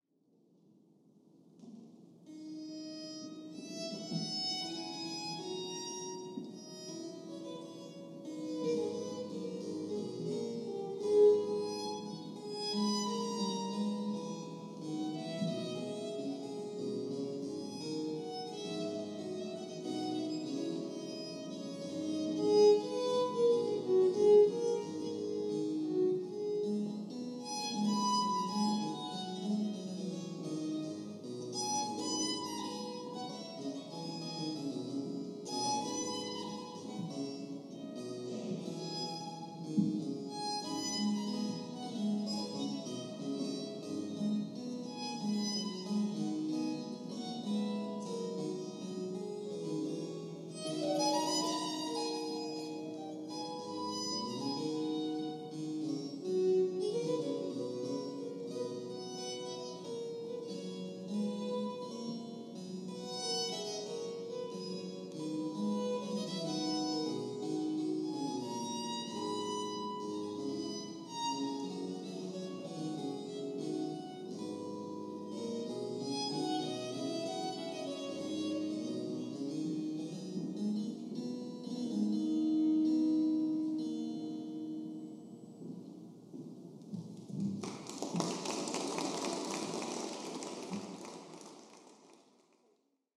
Live (lo-fi recording) at the British Museum